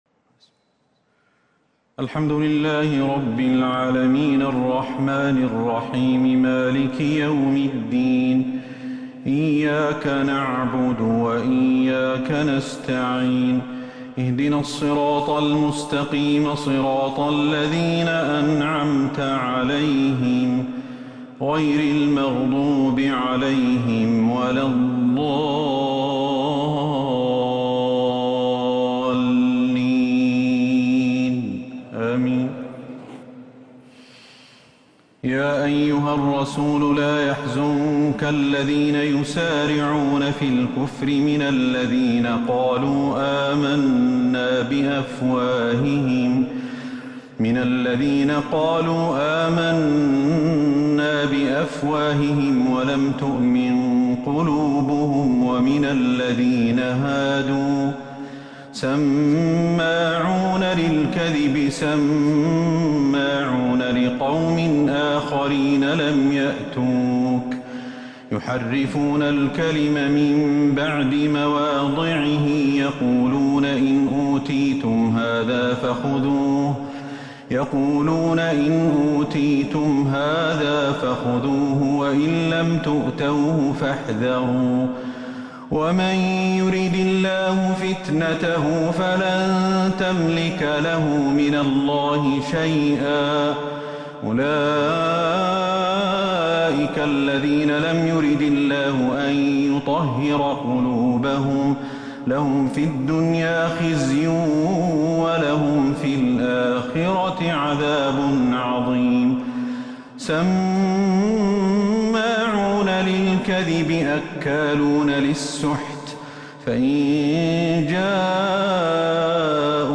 تراويح ليلة 8 رمضان 1442هـ من سورة المائدة [41-81] Taraweeh 8th night Ramadan 1442H > تراويح الحرم النبوي عام 1442 🕌 > التراويح - تلاوات الحرمين